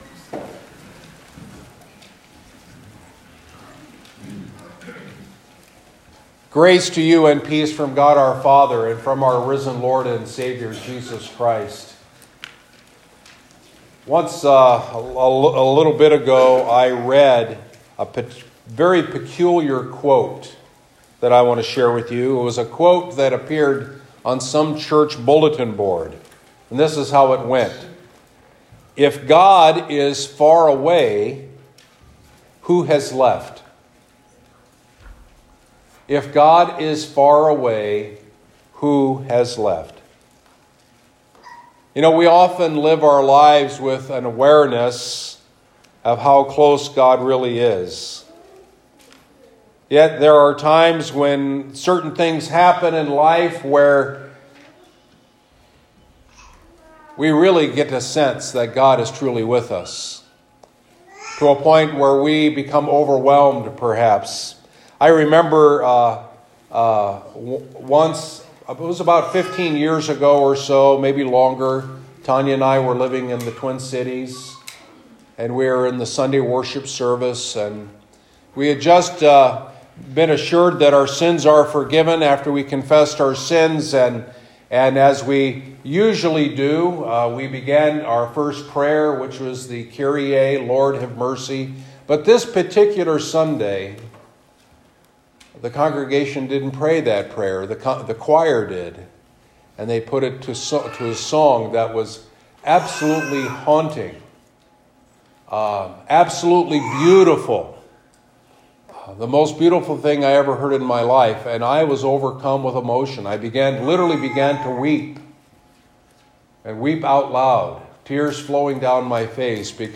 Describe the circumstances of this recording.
Christ Lutheran Church & School — 22 December 2019